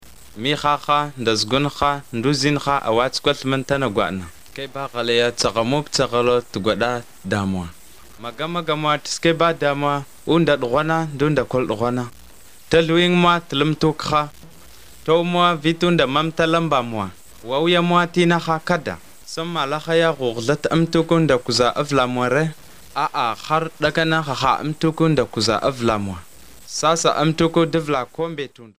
Most use a storytelling approach. These are recorded by mother-tongue speakers